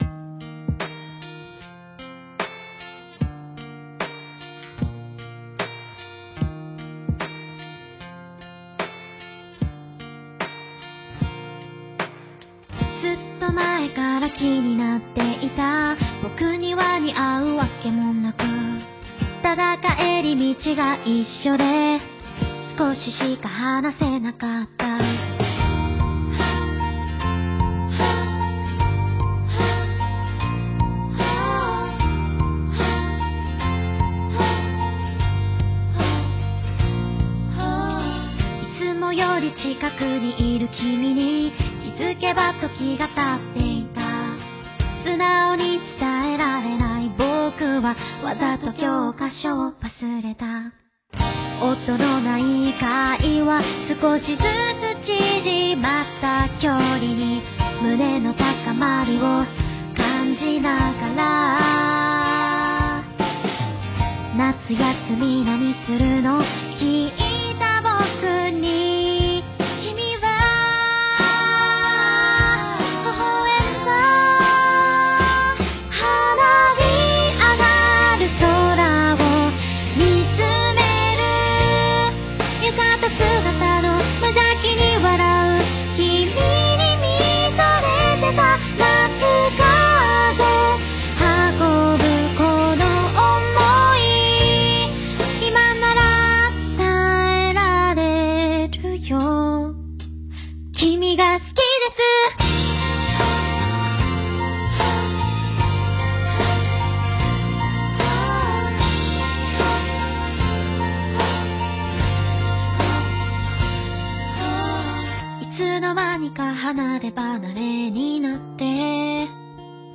スッと耳に入る歌声の持ち主でliveでは観客の心を掴む明るいMCも注目の一つ。